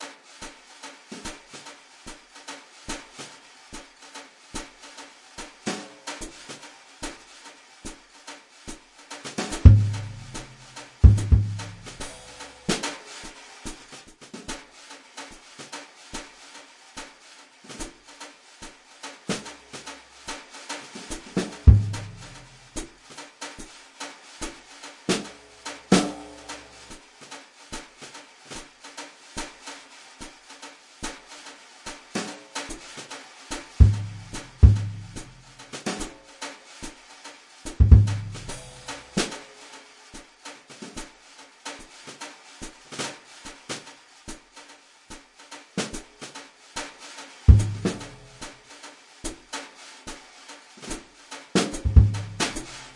cubeatz piano type 145bpm
Tag: 145 bpm Trap Loops Piano Loops 2.23 MB wav Key : B FL Studio